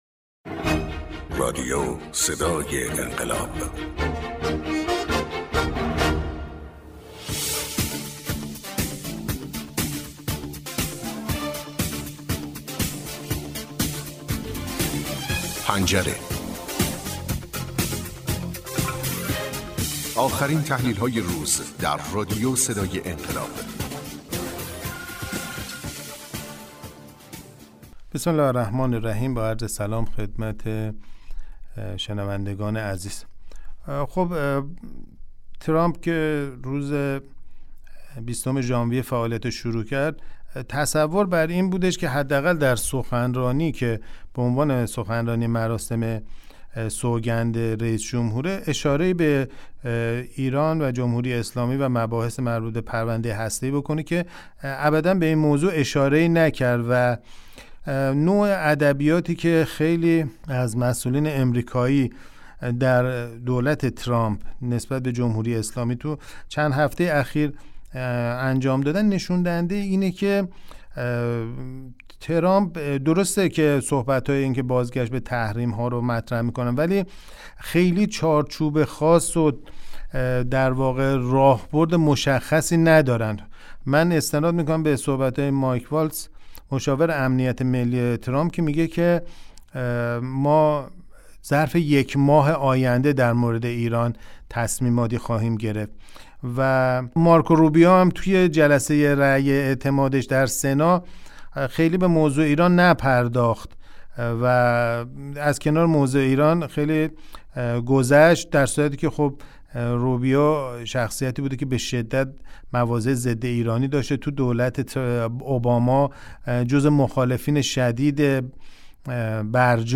کارشناس مسائل سیاسی